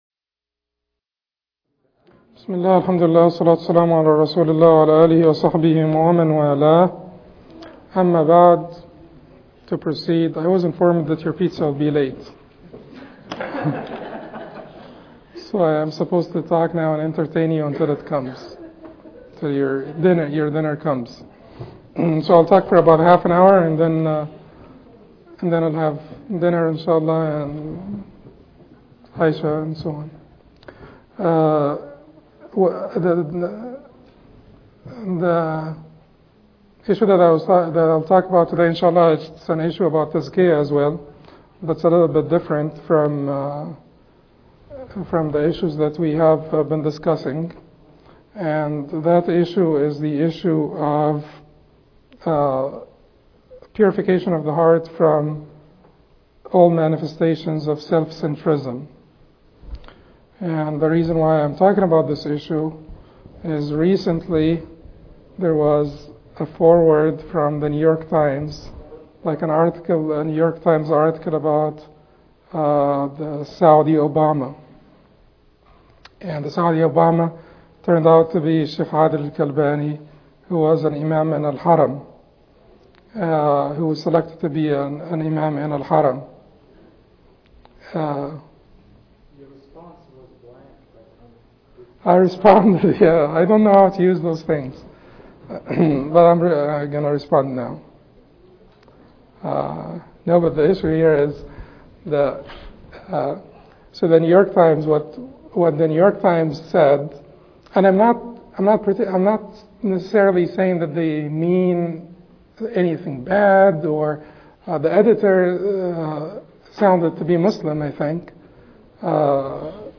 Examples of equality from the first generations Lecture